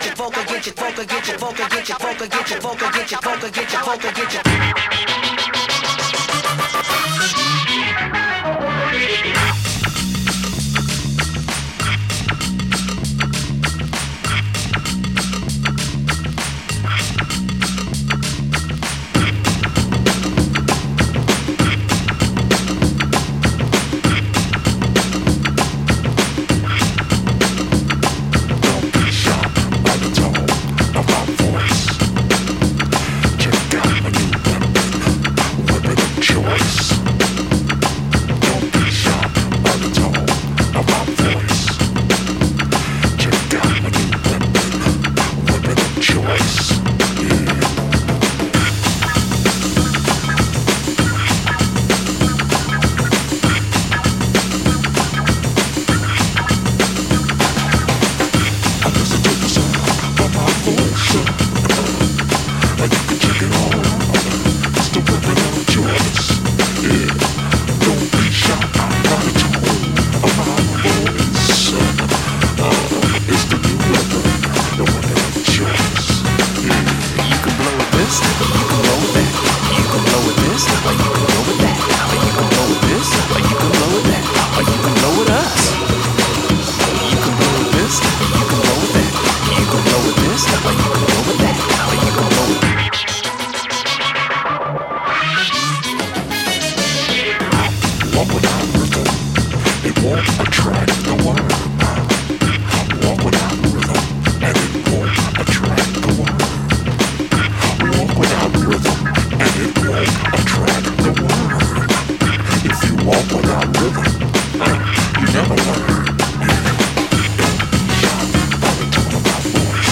Styl: House, Techno, Breaks/Breakbeat